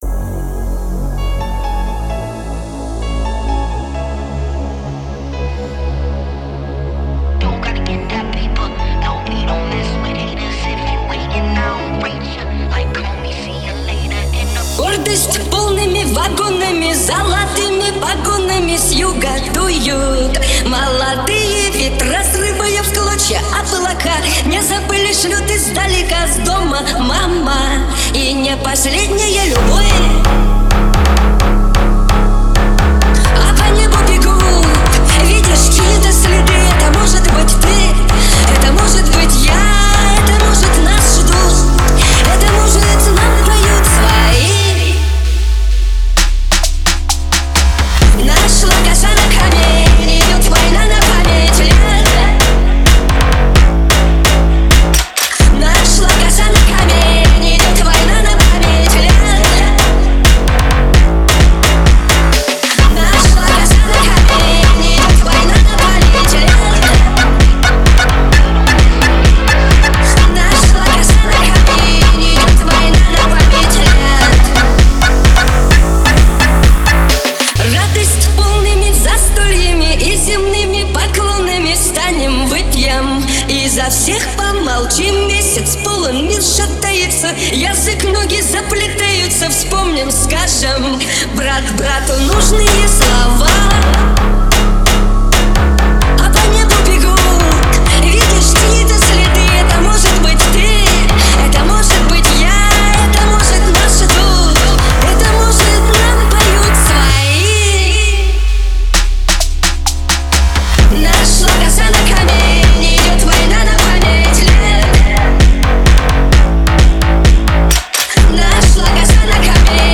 Фонк музыка